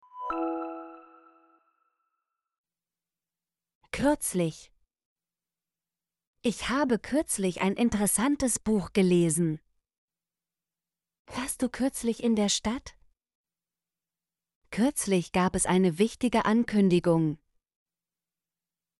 kürzlich - Example Sentences & Pronunciation, German Frequency List